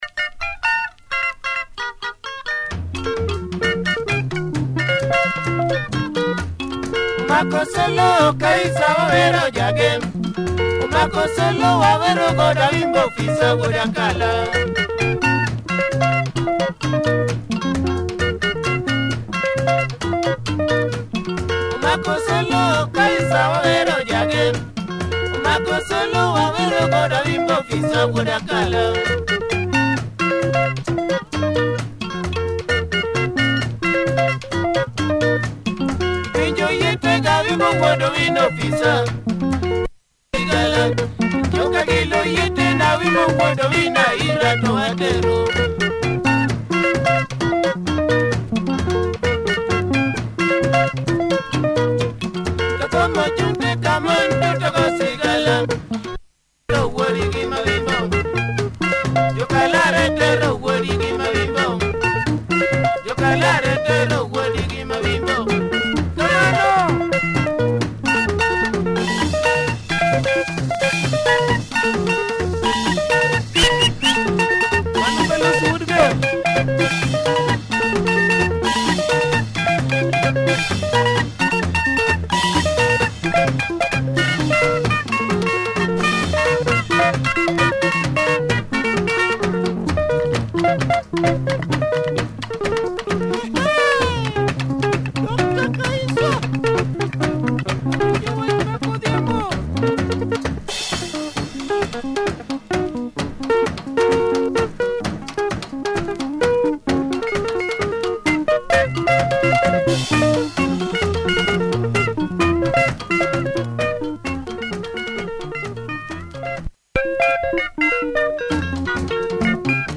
Nice drive in this LUO benga number, good production!